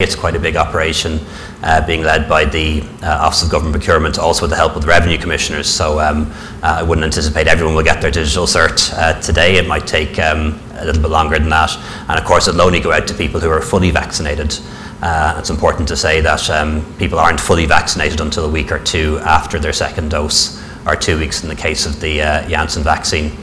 An Tánaiste Leo Varadkar says it will take time to get the certs sent out to everyone: